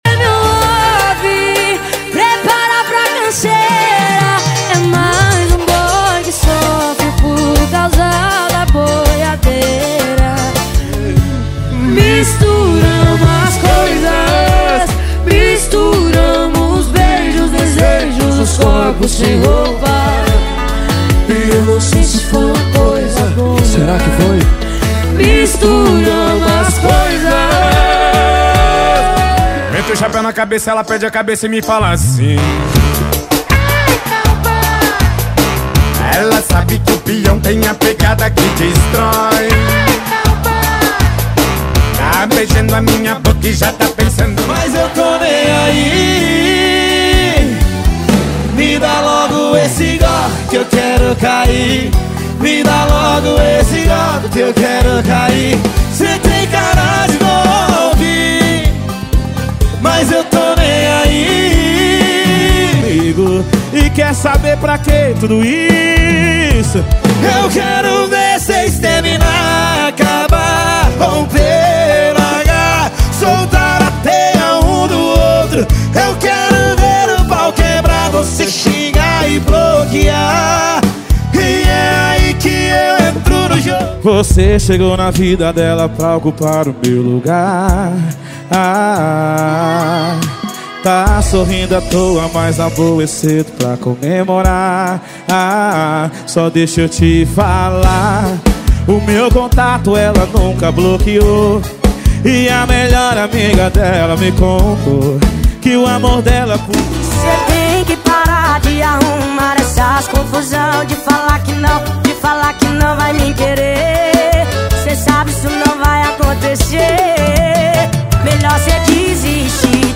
Os Melhores Sertanejo do momento estão aqui!!!
• Sem Vinhetas
• Em Alta Qualidade